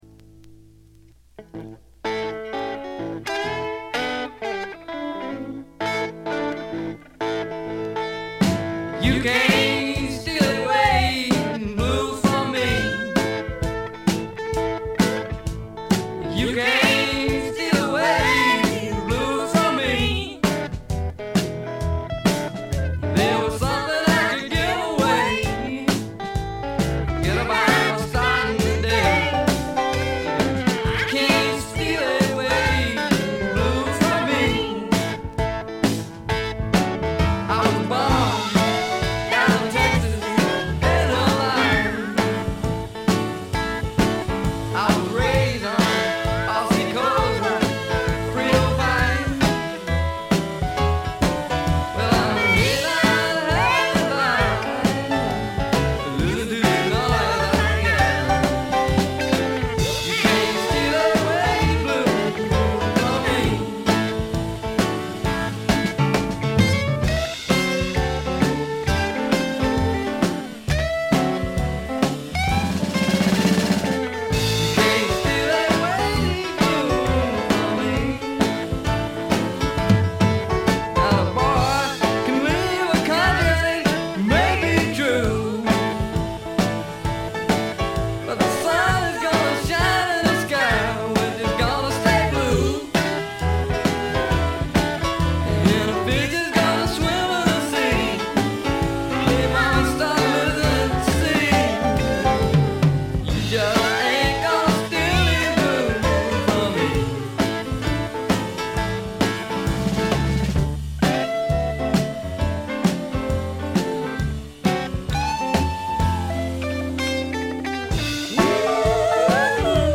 ごく微細なノイズ感のみ。
バンドサウンドとしてはこちらの方が上かな？
米国スワンプ基本中の基本！
試聴曲は現品からの取り込み音源です。